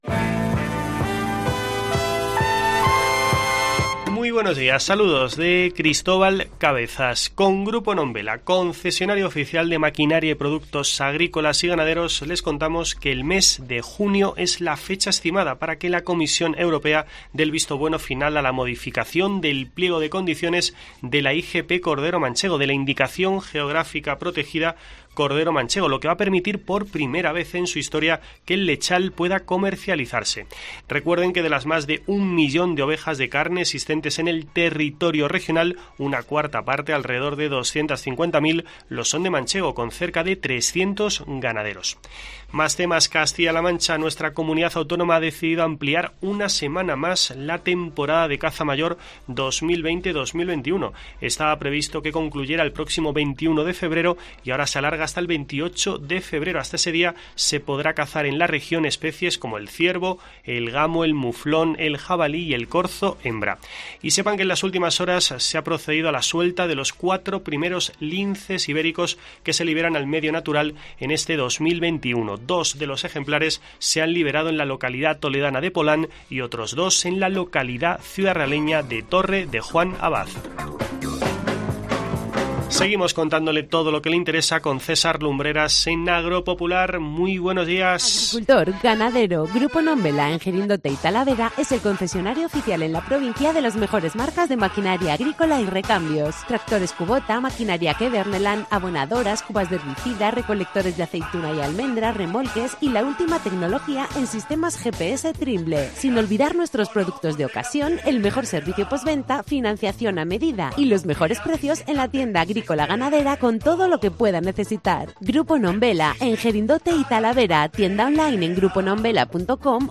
Escucha en la parte superior de esta noticia toda la actualidad del mundo del campo en nuestro boletín informativo semanal.